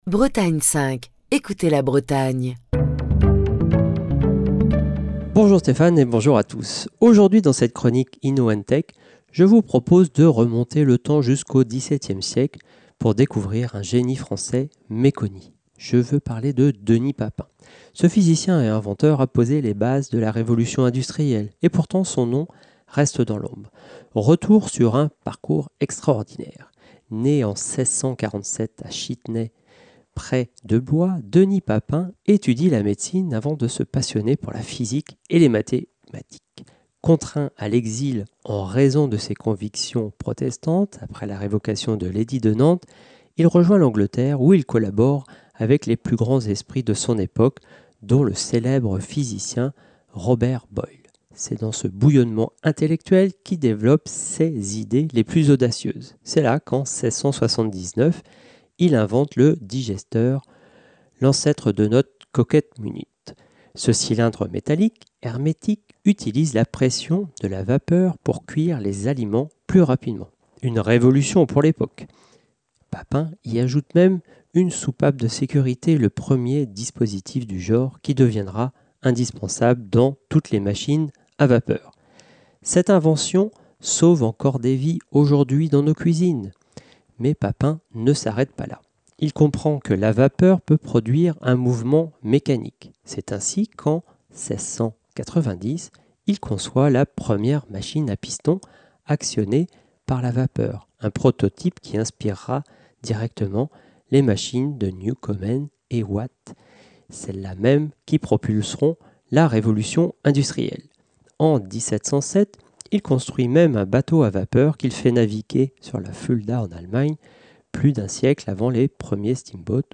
Chronique du 16 décembre 2025.